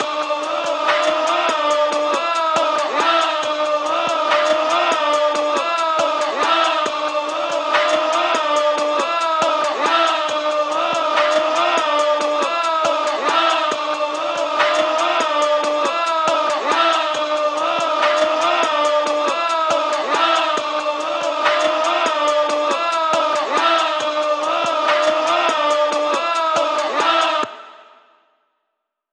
CAROUSEL VOX LOOP.wav